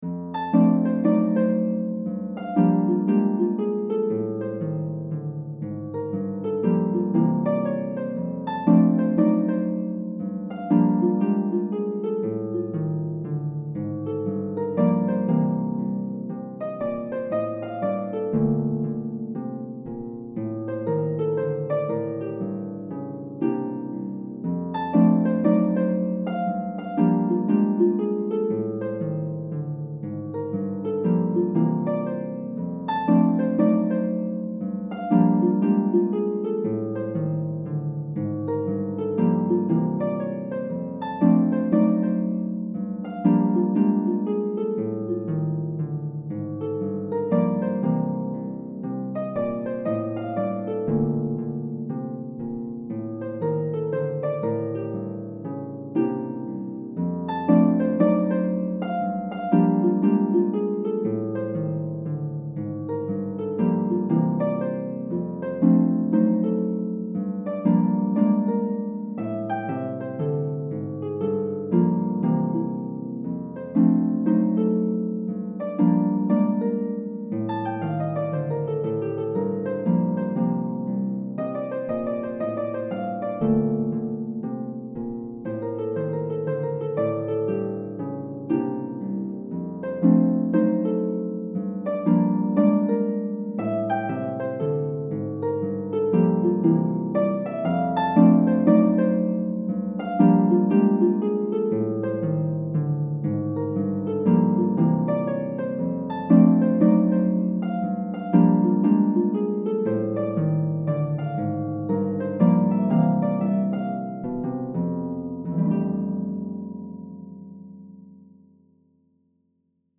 midi sample